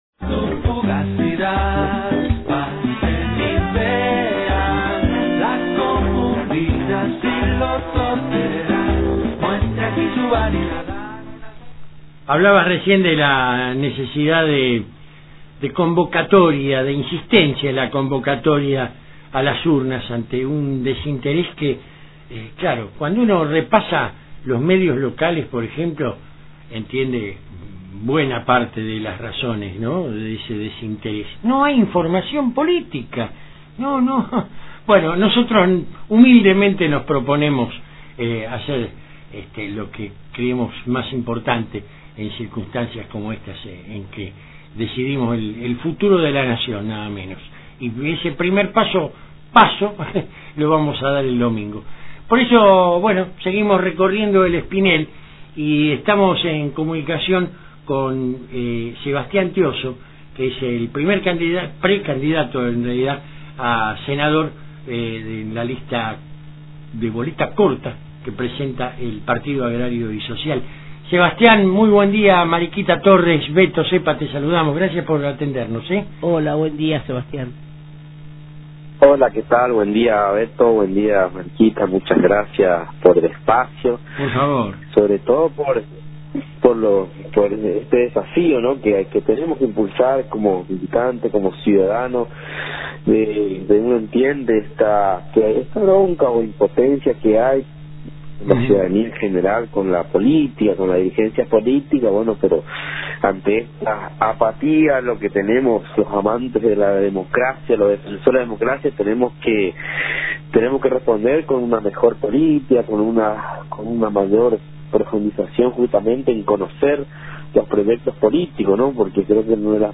Tiozzo fue entrevistado desde el programa Contala como quieras, en La 99.3